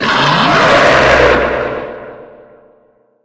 Divergent / mods / Soundscape Overhaul / gamedata / sounds / monsters / lurker / attack_0.ogg
attack_0.ogg